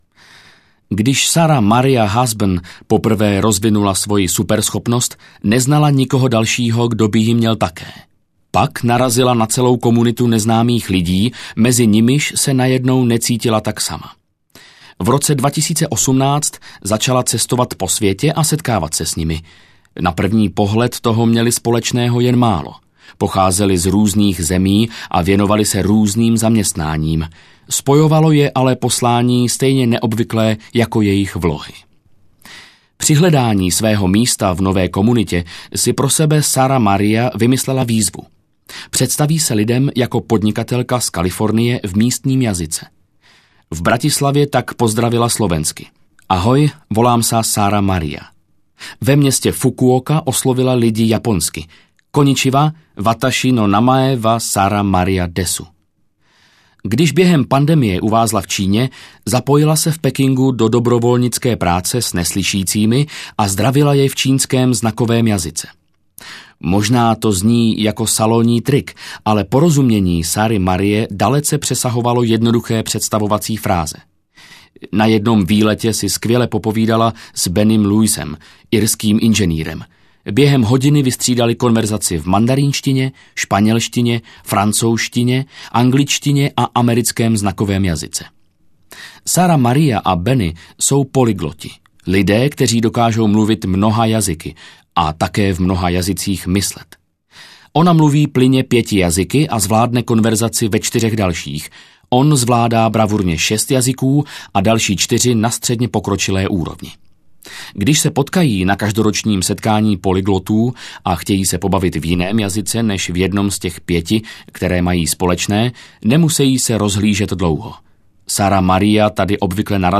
Audiokniha Skrytý potenciál - Adam Grant | ProgresGuru